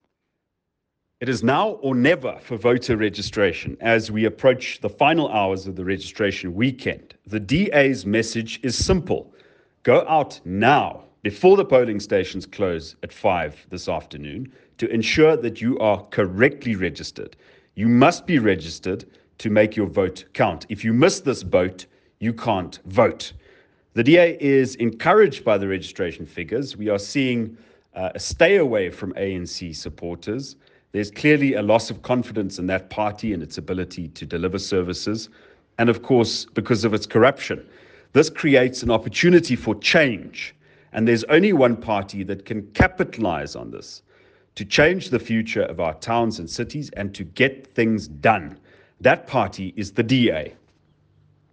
Afrikaans soundbites by Cilliers Brink MP.